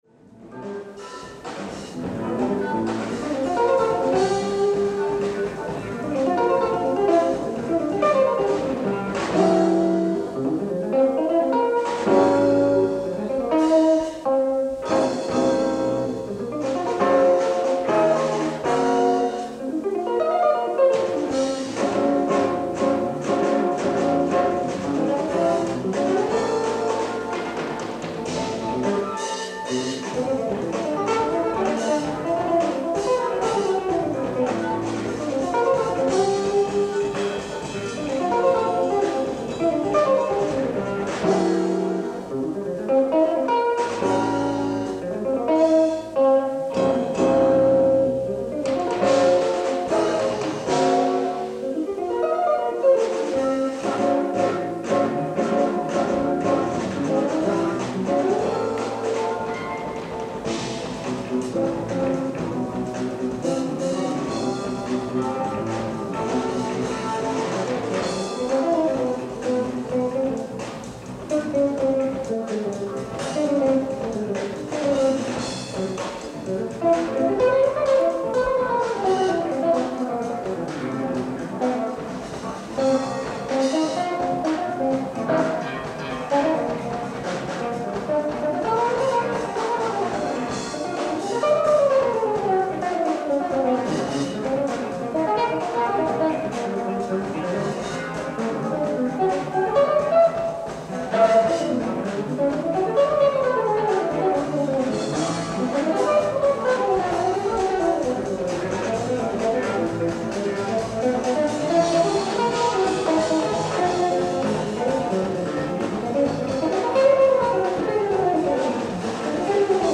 ライブ・アット・バークリー、ボストン 03/25/1977
※試聴用に実際より音質を落としています。